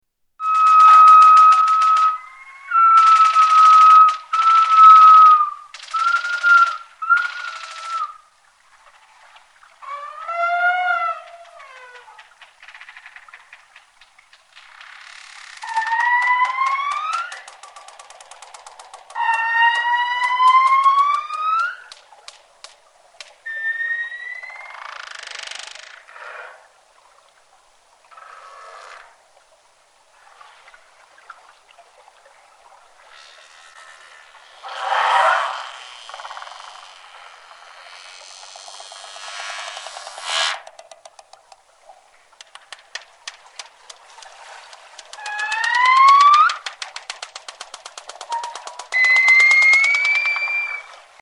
Whale Killer Clicks W AT039701
Category: Animals/Nature   Right: Personal